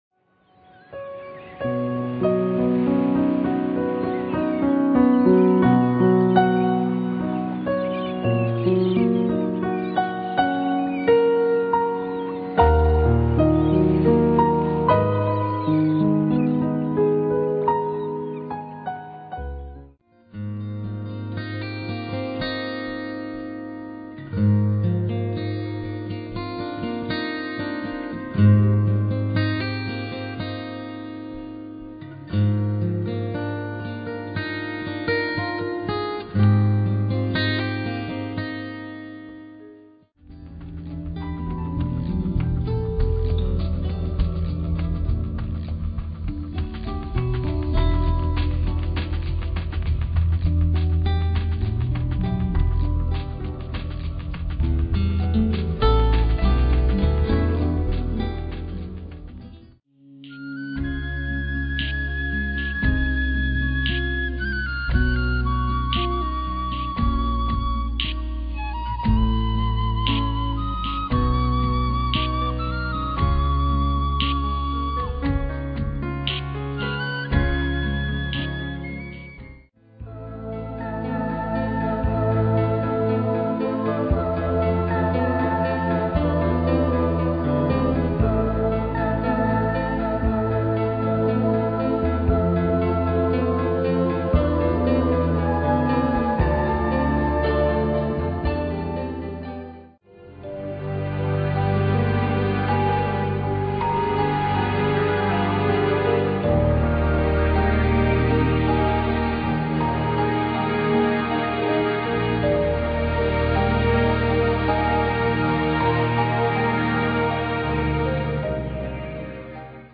Relaxation